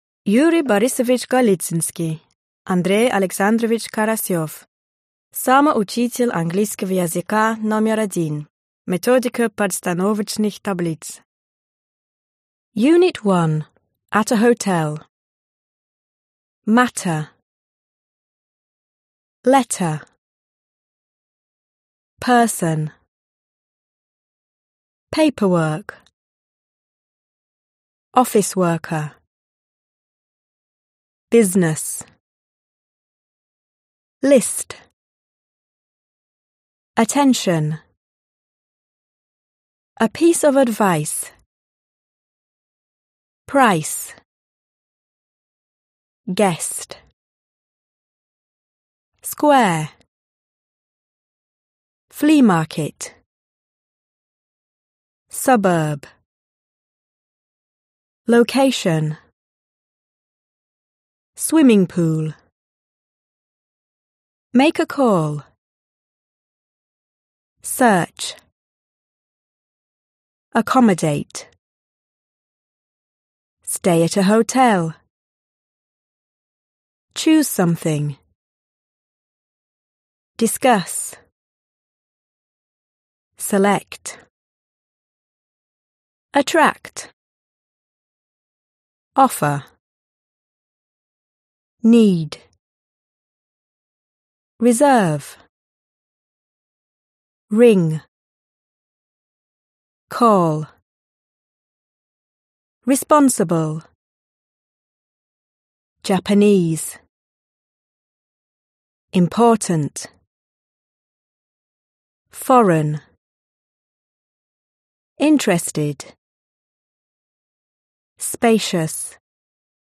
Аудиокнига Самоучитель английского языка №1. Книга 2. Уровень Pre-Intermediatе | Библиотека аудиокниг